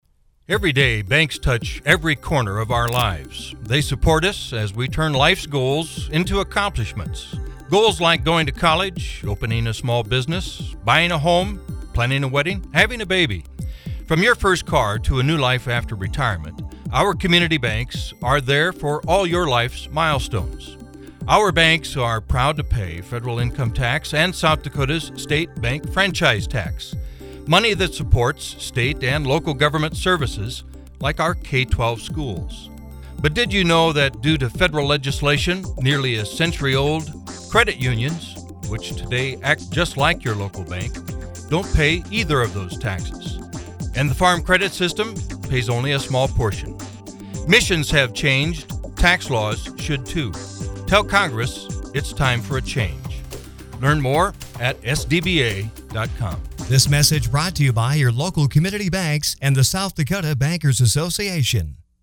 Sample 60-Second Radio Ad
sampleradioad.mp3